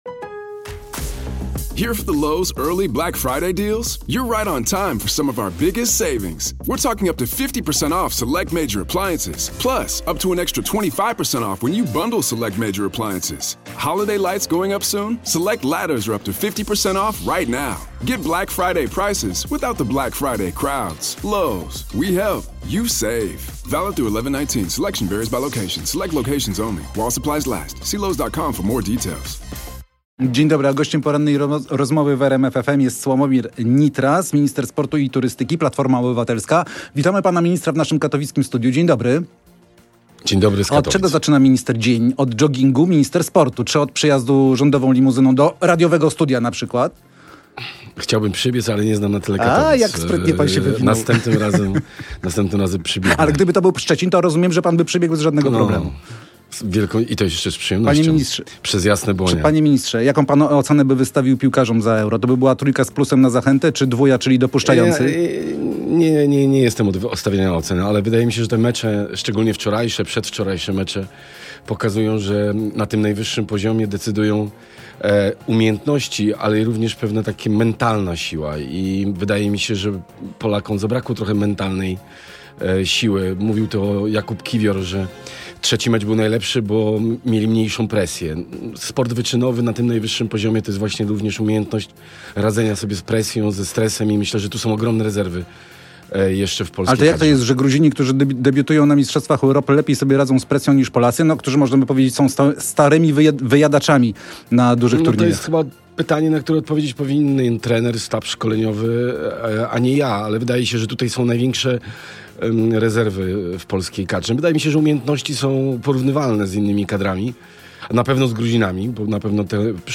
Odsłuchaj starsze transmisje RMF FM!